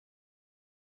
silence1.wav